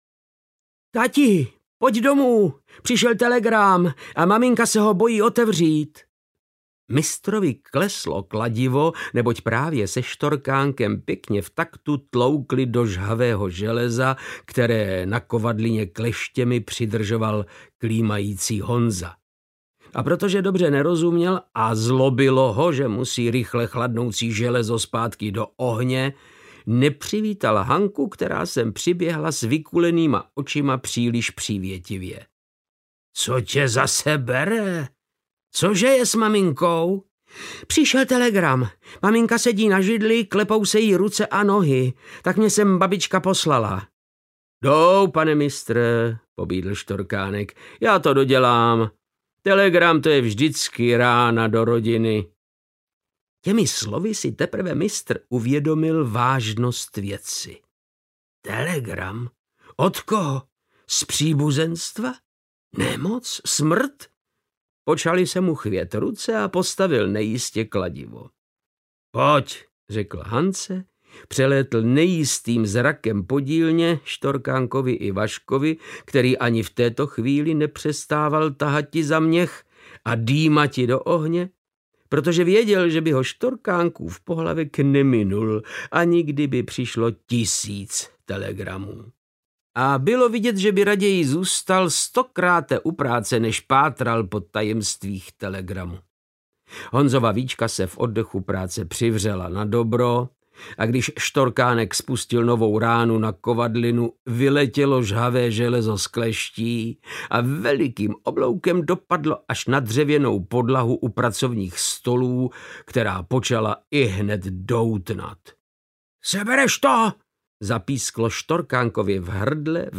Tygr pana Boška audiokniha
Ukázka z knihy
• InterpretVáclav Knop